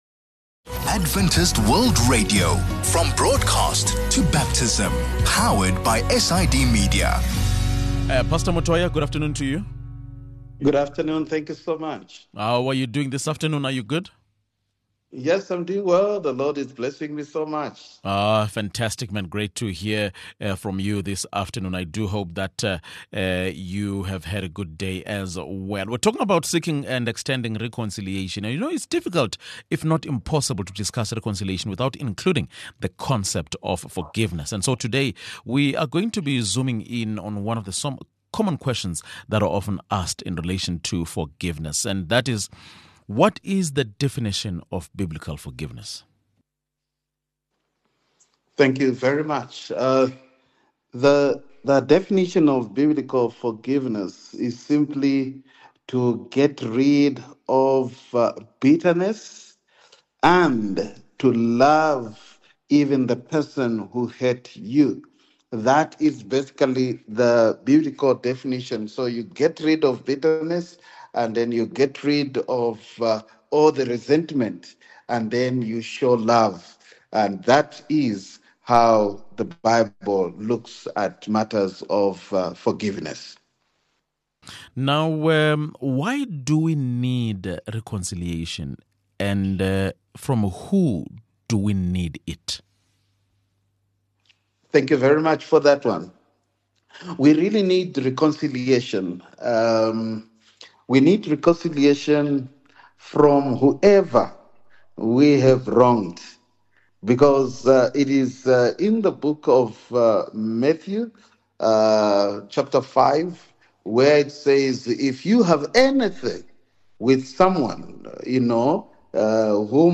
It’s difficult, if not impossible, to discuss reconciliation without including the concept of forgiveness. In today’s conversation, we’re going to zoom in on some common questions that are often asked in relation to forgiveness.